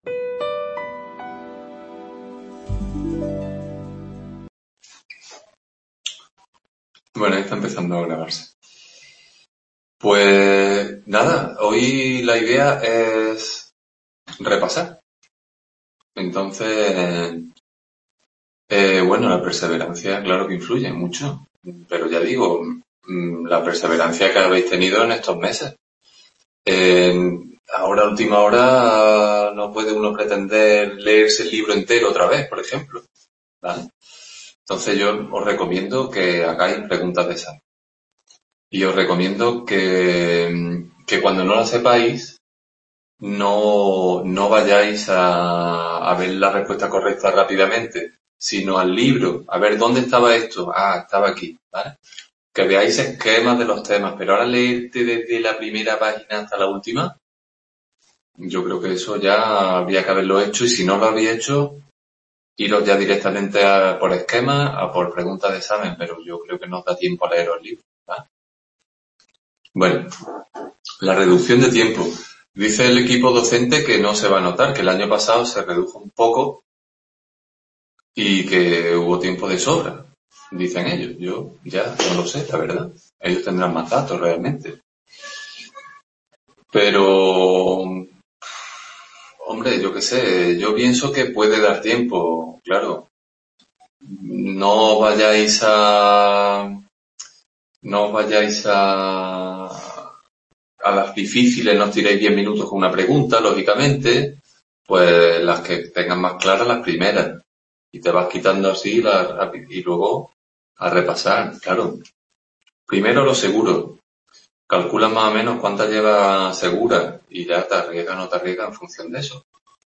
Description Tutoría de Psicología del Aprendizaje en Córdoba.